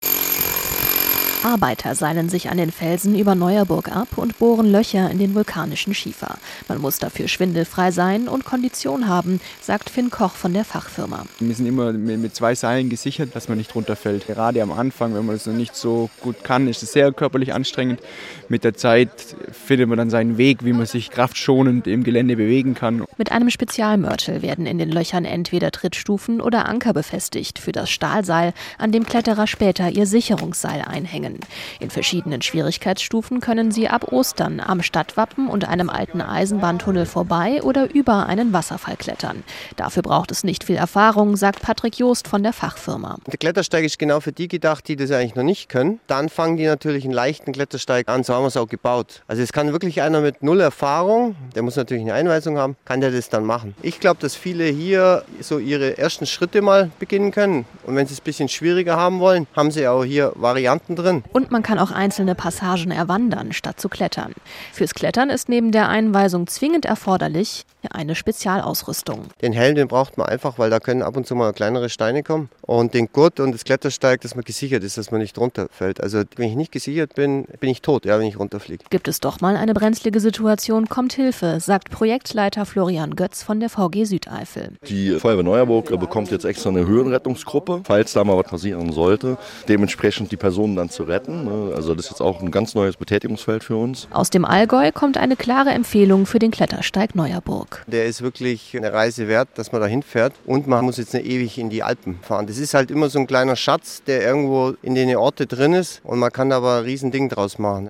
In der Felswand erklingt Allgäuer Dialekt.